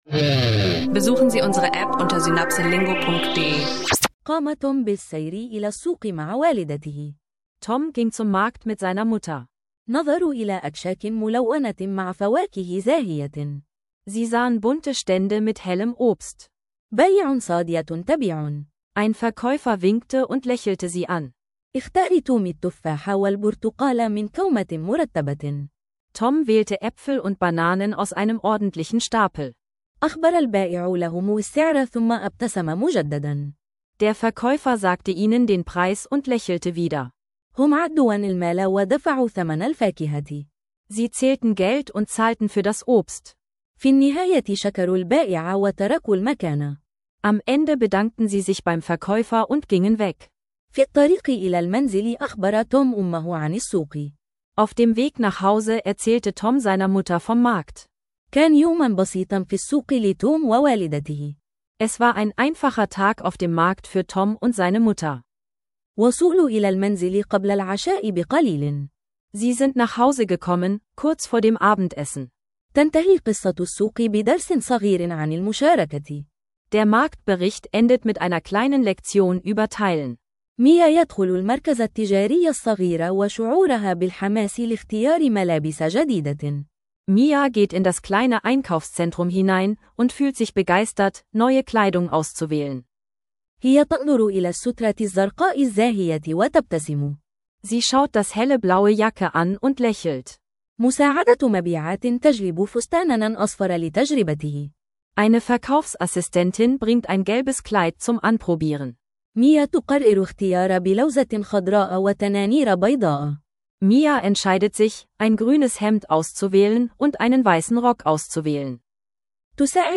Praktische Alltagssituationen auf Arabisch: Markt, Kleidung, europäischer Fußballweg und Recht – mit interaktiven Übungen und einfachen Dialogen.